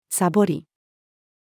サボり-female.mp3